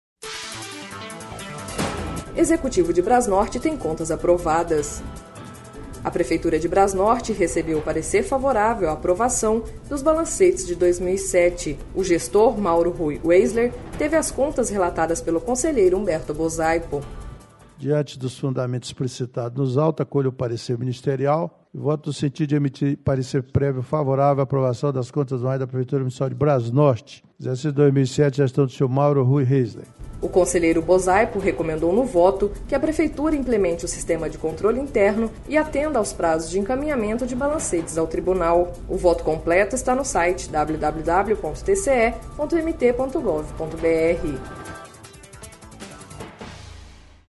Sonora: Humberto Bosaipo – conselheiro do TCE-MT